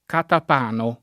vai all'elenco alfabetico delle voci ingrandisci il carattere 100% rimpicciolisci il carattere stampa invia tramite posta elettronica codividi su Facebook catapano [ katap # no ] o catepano [ katep # no ] s. m. (stor.)